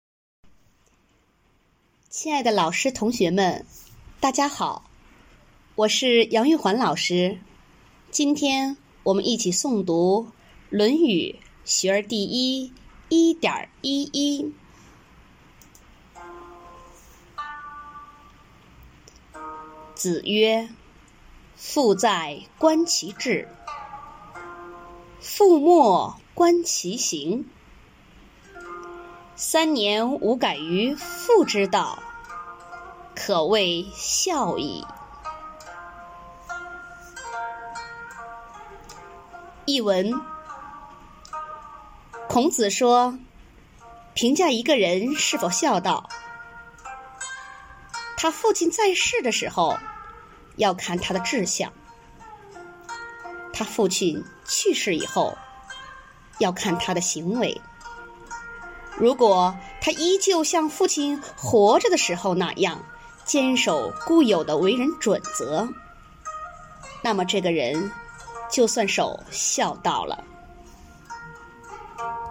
每日一诵0302.mp3